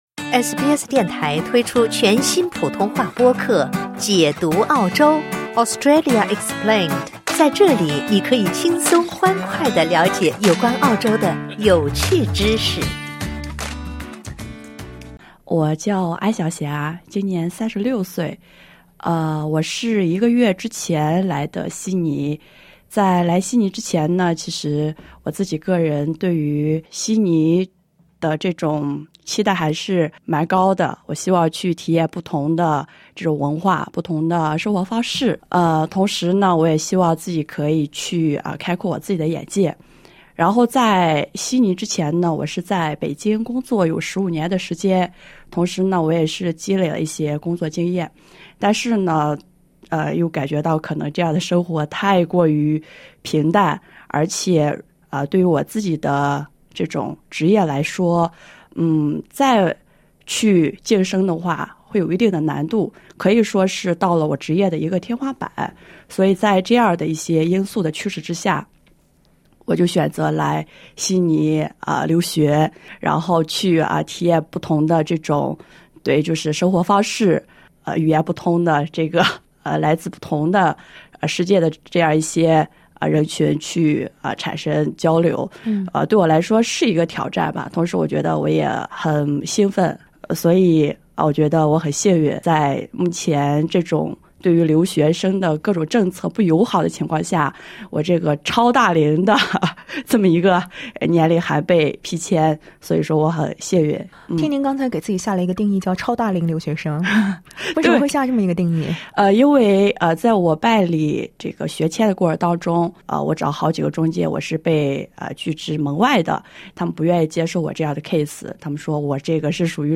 请点击收听完整采访： LISTEN TO “大龄”留学生自述：限制政策频出 我为何仍选择选择来澳读书？